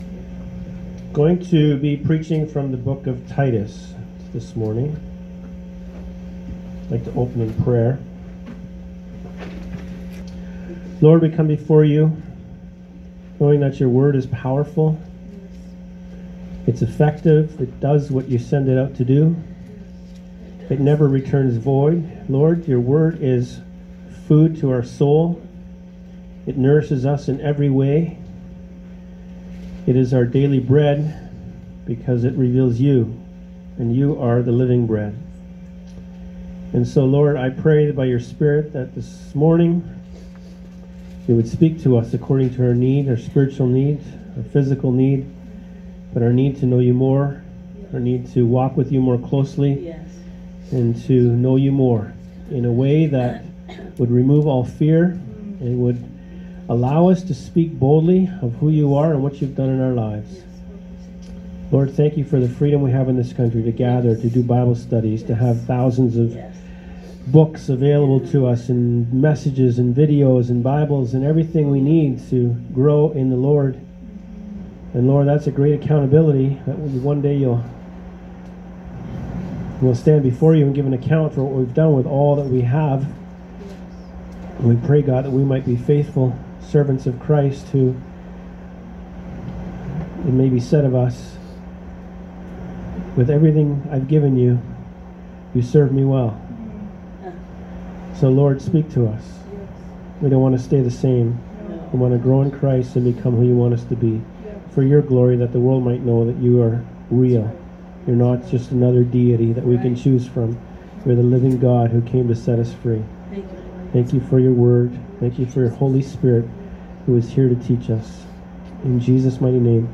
Audio Sermons - Freedom House Church and Healing Centre